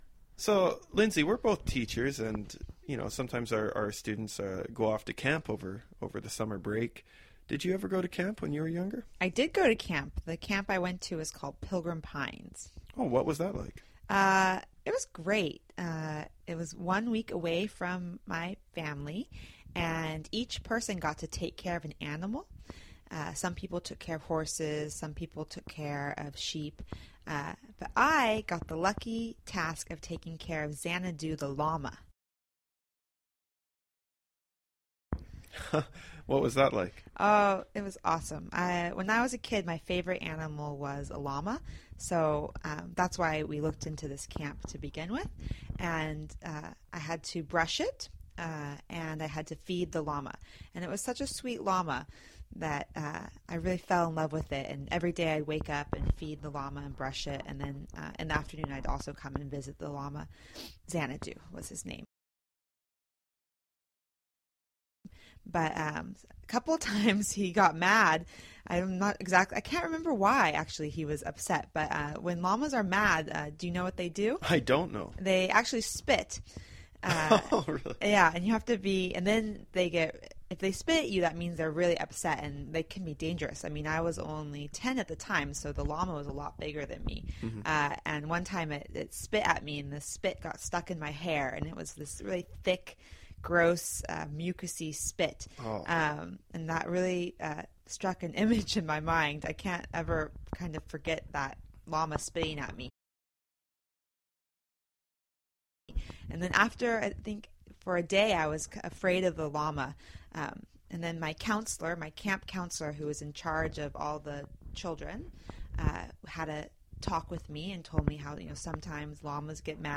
在线英语听力室英文原版对话1000个:1107 Girls Camp的听力文件下载,原版英语对话1000个,英语对话,美音英语对话-在线英语听力室